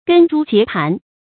根株結盤 注音： ㄍㄣ ㄓㄨ ㄐㄧㄝ ˊ ㄆㄢˊ 讀音讀法： 意思解釋： 樹木的根與干盤曲相結。比喻關系錯綜牢固。